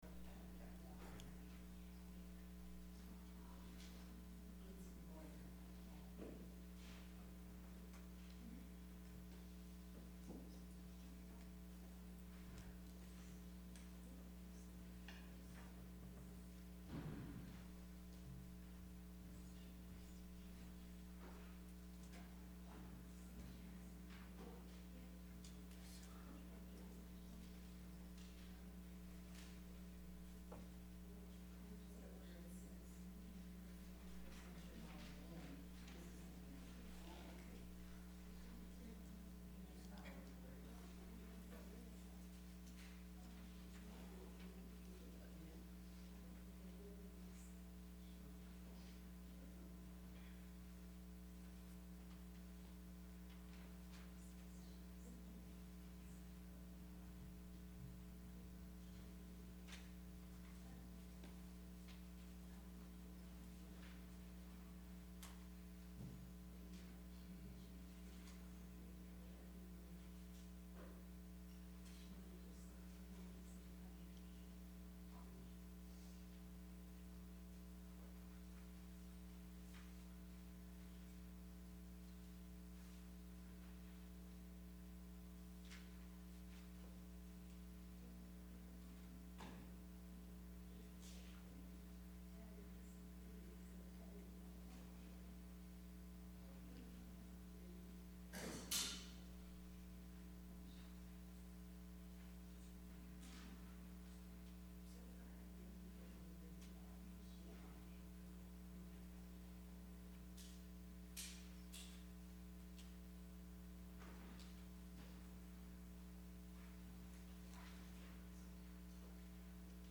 Sermon – April 18, 2019 – Advent Episcopal Church